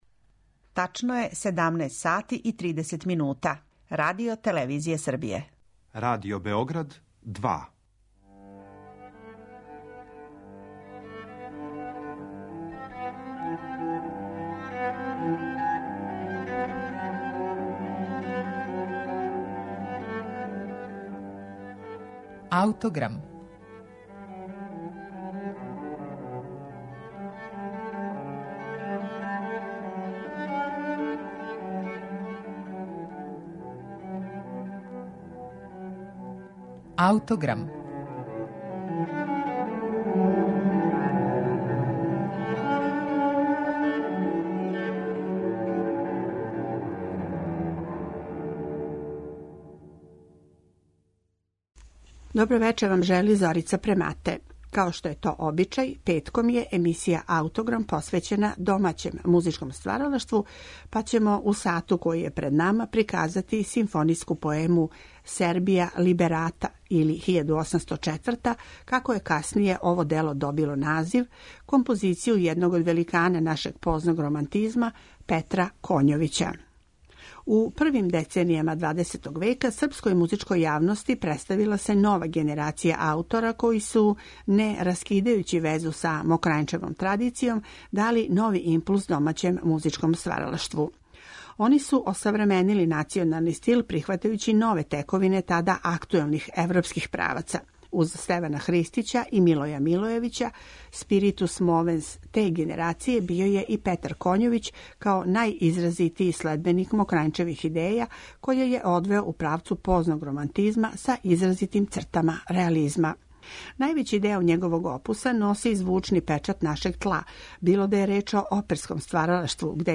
прву симфонијску поему у историји српске музике